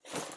（*：拾取投掷物的音效过于机械，建议更换
拾取投掷物.wav